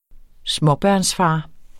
Udtale [ ˈsmʌbɶɐ̯ns- ]